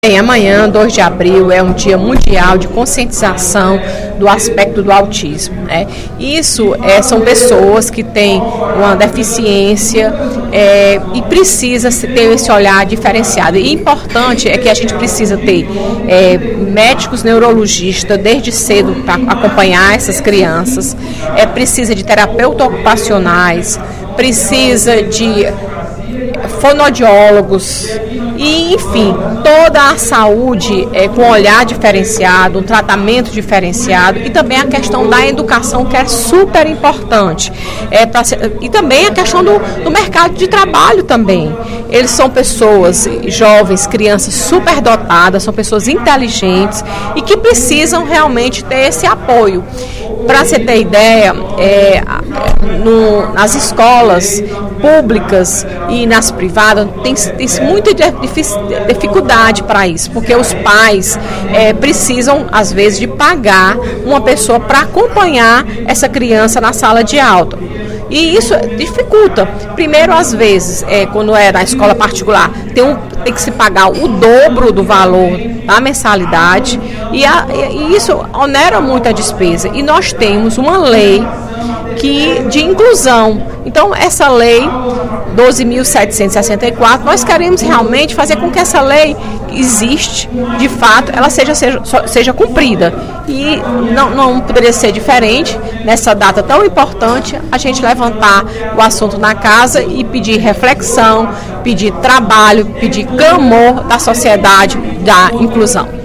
A deputada Fernanda Pessoa (PR) fez pronunciamento nesta sexta-feira (01/04), no primeiro expediente da sessão plenária da Assembleia Legislativa, para destacar o Dia Mundial de Conscientização do Autismo, que transcorrerá amanhã.